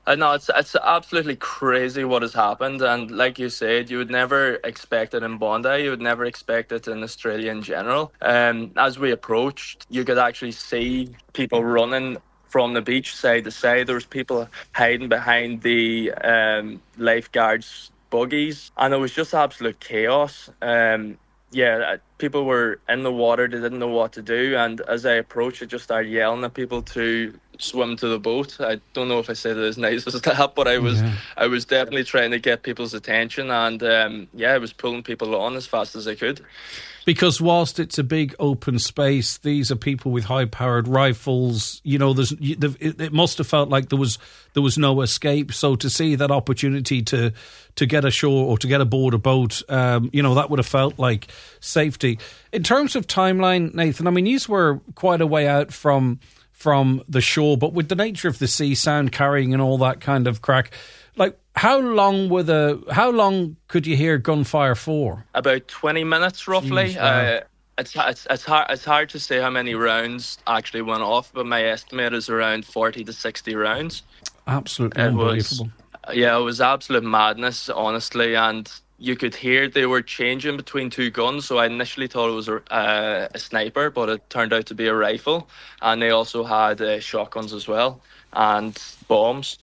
recounted his experience of witnessing and helping people escape the attack on today’s nine til noon show…..